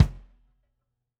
Index of /musicradar/Kicks/Kes Kick
CYCdh_KesKick-06.wav